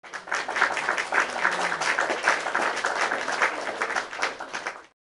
tepuk tangan tk Meme Sound Effect
tepuk tangan tk.mp3